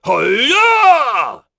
Lusikiel-Vox_Attack4.wav